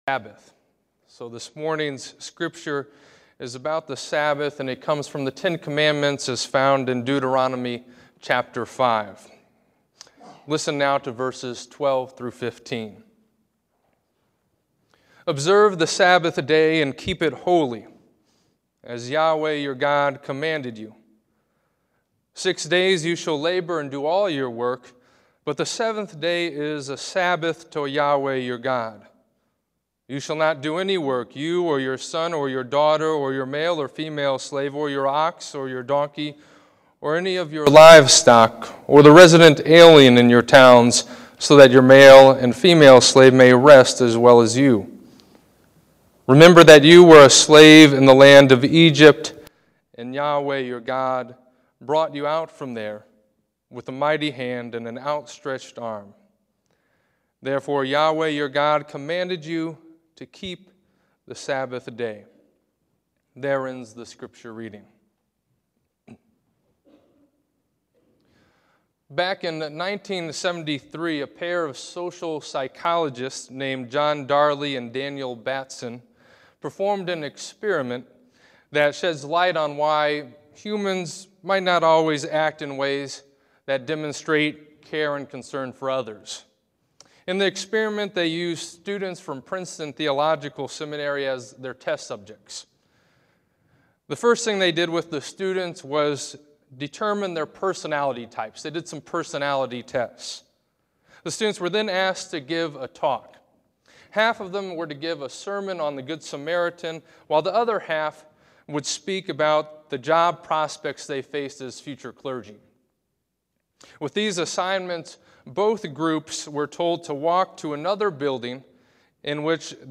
This sermon is the first of a series exploring the meaning of sabbath, the day of rest.